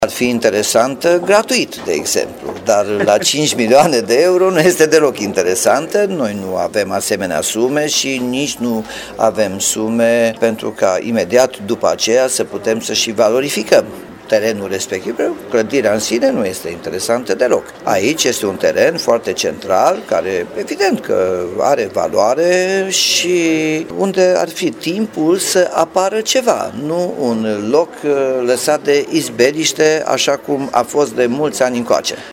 Primarul Nicolae Robu susține că primăria ar fi interesată de teren și clădire doar dacă le primește cadou: